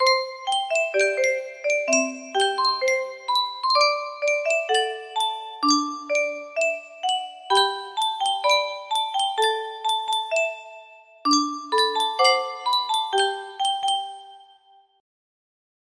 Yunsheng Music Box - ILOAFLC 1083 music box melody
Full range 60